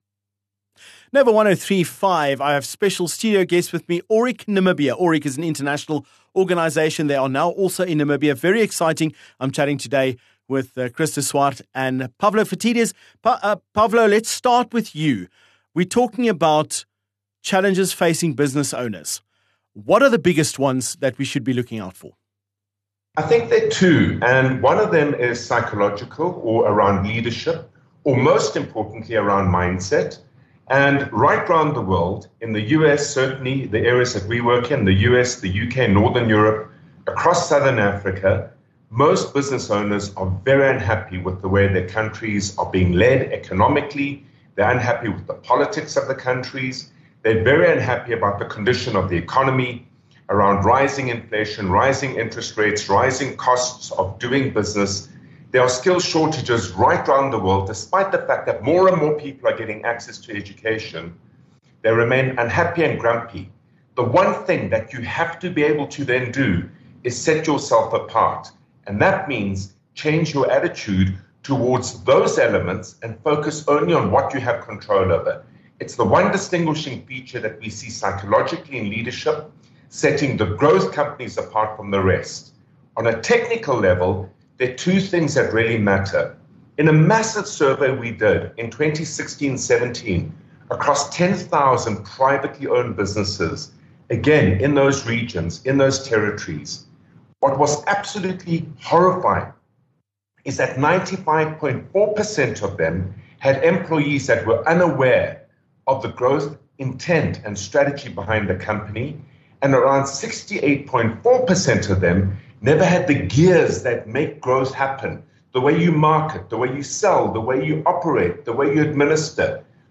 17 Oct Aurik Namibia Interview Part 1